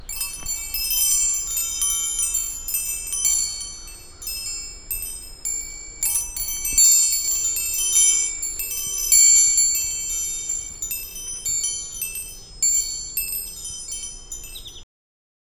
windchime2.R.wav